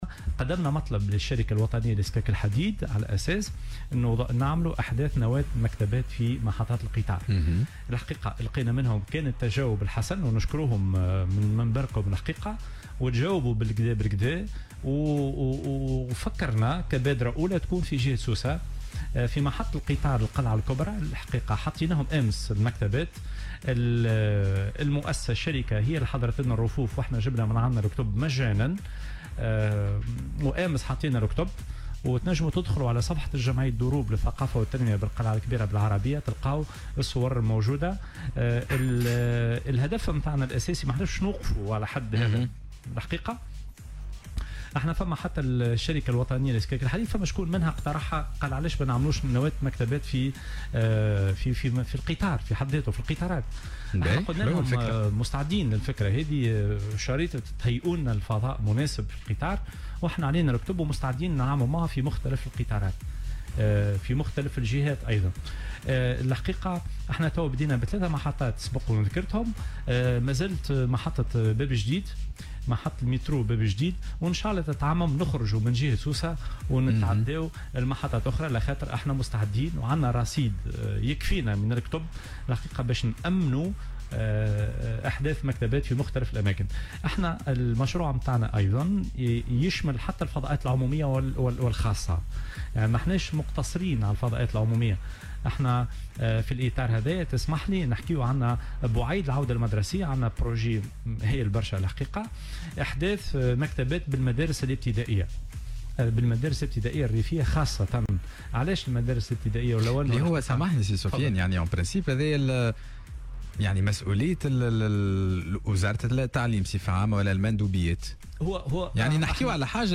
وأوضح في مداخلة له اليوم على "الجوهرة أف أم" أن الهدف الأساسي من هذه المبادرة هو التشجيع على المطالعة، مشيرا أيضا إلى أن الجمعية ستقوم بإحداث مكتبات داخل 12 مدرسة ابتدائية (6 مدارس في القلعة الكبرى و6 مدارس في النفيضة).وأكد أن هذه المجهودات تأتي في إطار شراكة مع المندوبية الجهوية للثقافة بسوسة والشركة الوطنية للسكك الحديدية والمندوبية الجهوية للتربية، وكذلك بالشراكة مع بعض الخواص.